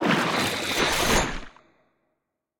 Sfx_creature_brinewing_eat_01.ogg